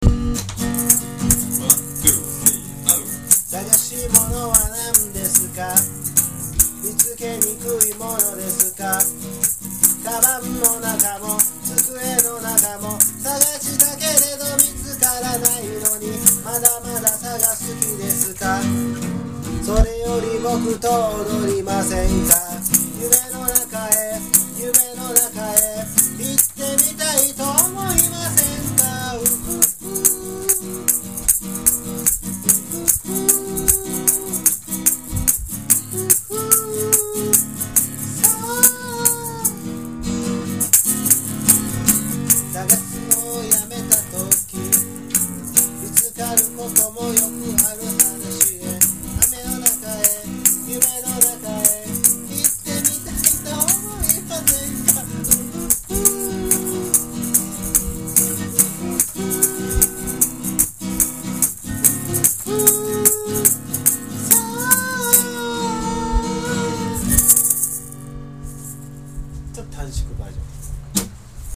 この唄と、グラスを磨く音、そしてグラスラックに仕舞う音だけが響いていた。